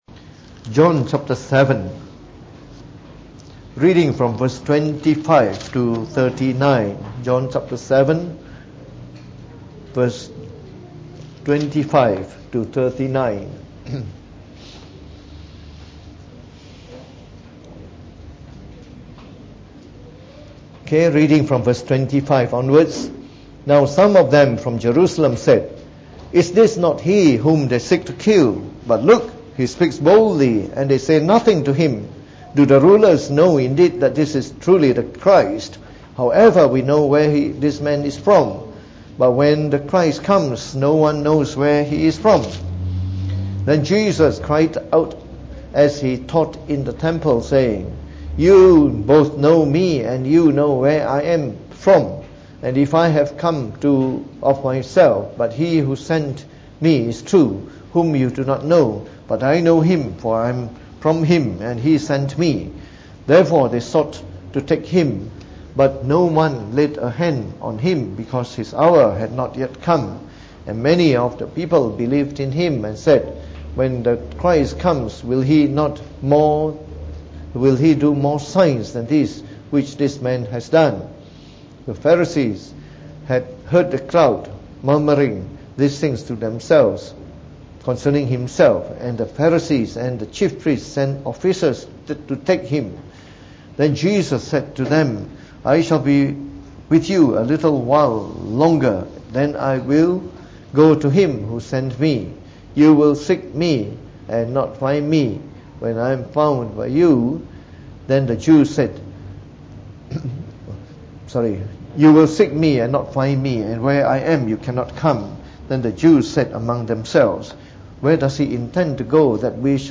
Preached on the 18th November 2018.